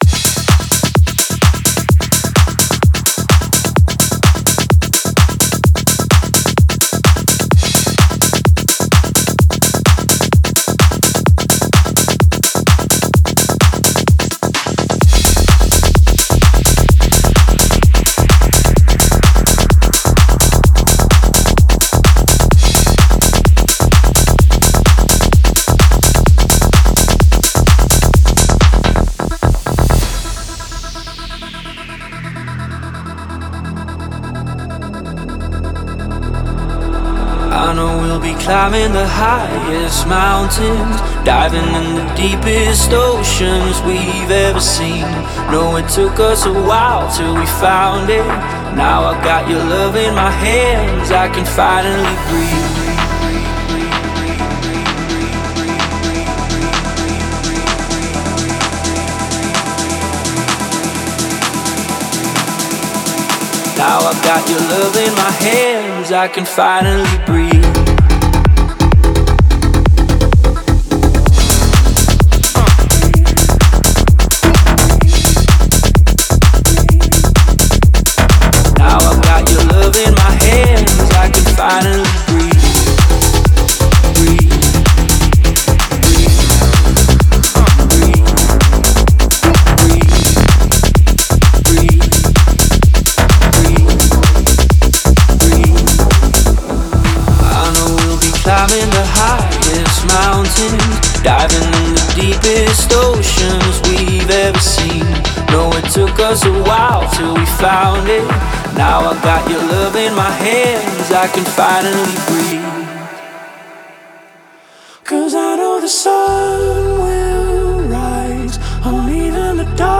Жанр: Club, Dance, Other